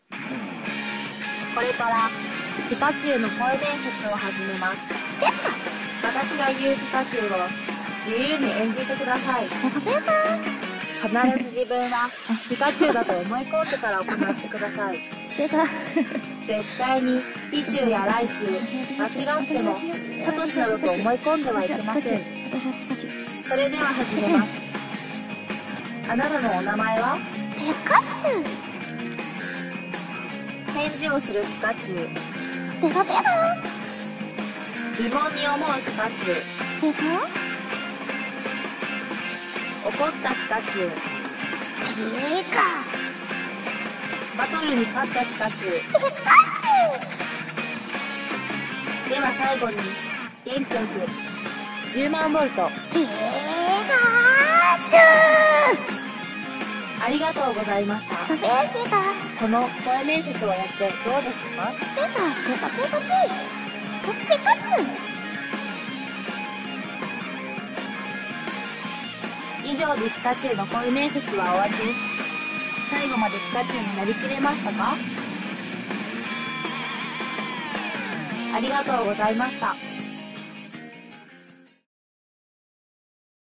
ピカチュウの声面接